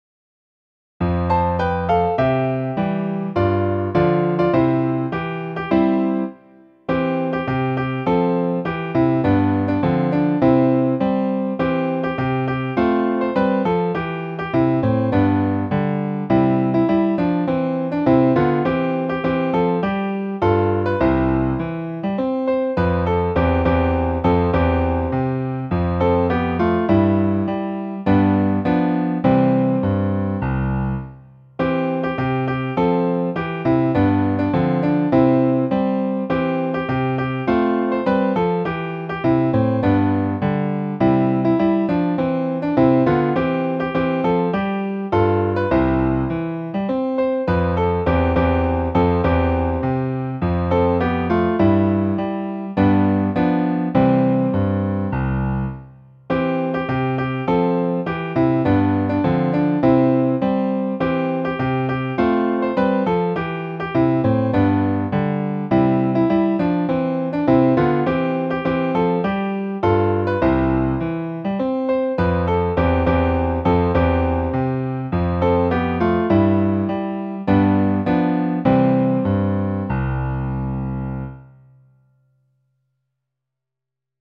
※園歌は、ピアノ伴奏が流れます。
園歌